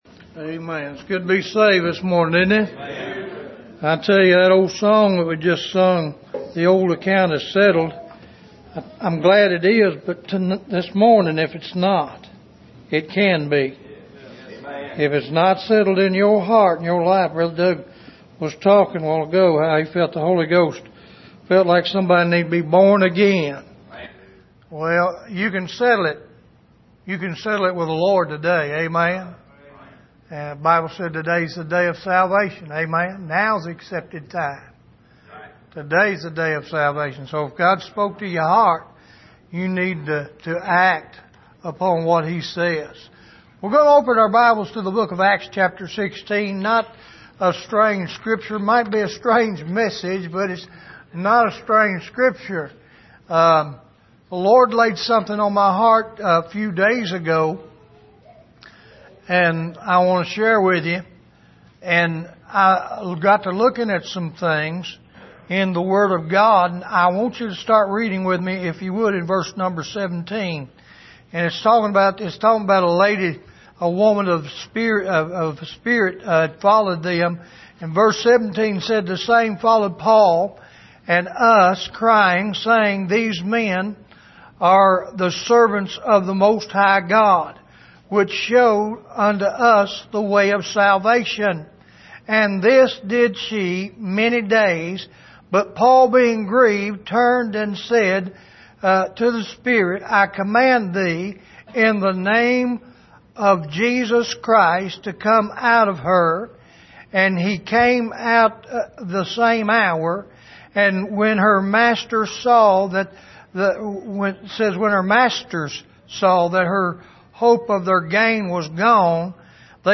Here is an archive of messages preached at the Island Ford Baptist Church.
Service: Sunday Morning